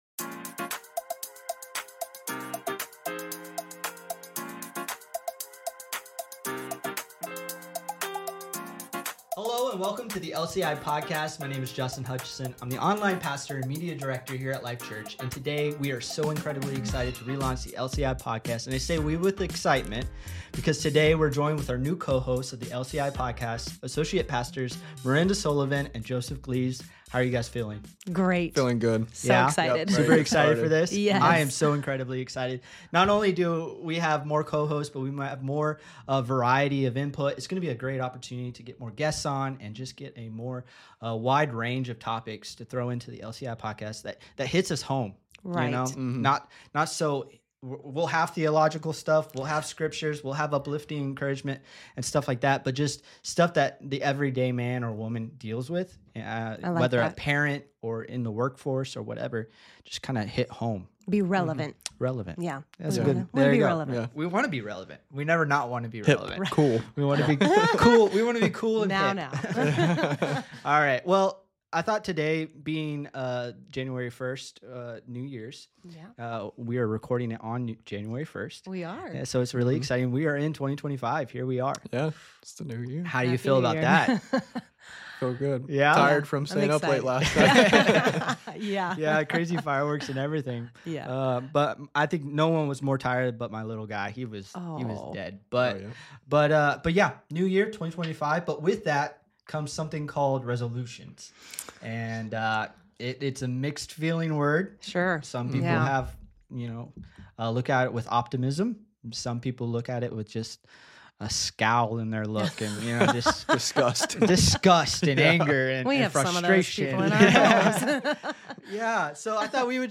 The LCI Podcast is a ministry of LifeChurch International in Gilbert, AZ. Listen in as we engage in discussions of various topics with guest speakers of all ages and experiences.